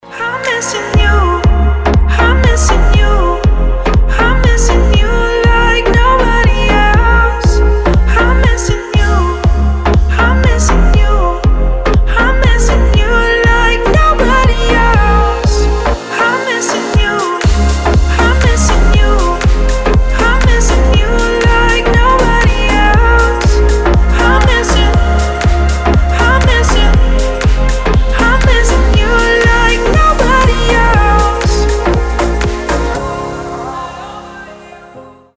• Качество: 320, Stereo
deep house
Chill